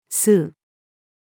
吸う-female.mp3